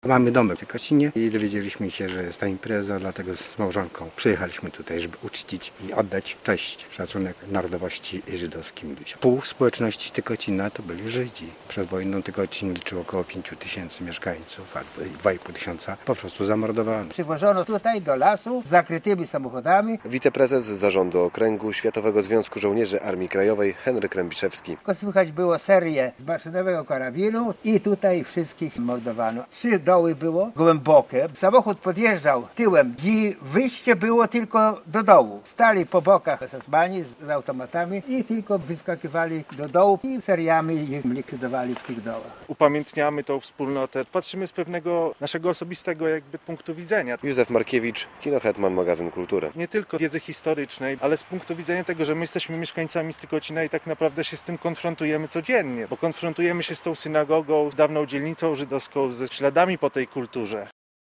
Relacja z uroczystości